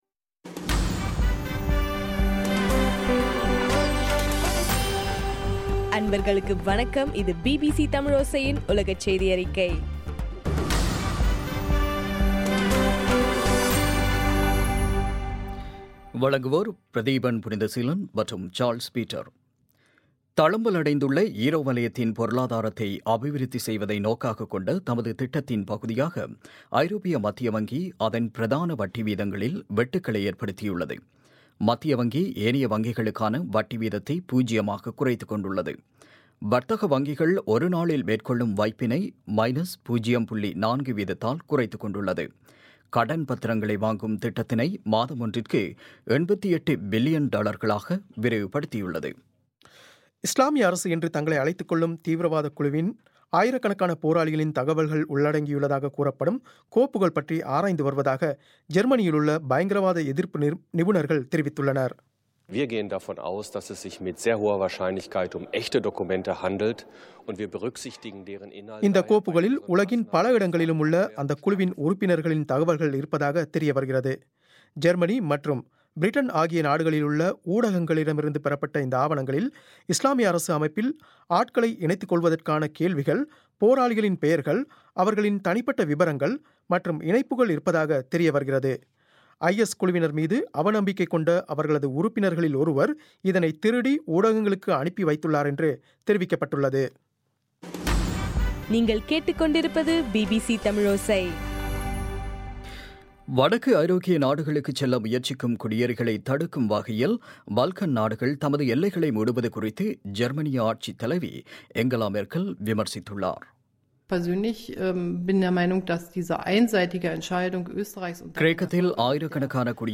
பிபிசி தமிழோசை- உலகச் செய்தியறிக்கை- மார்ச் 10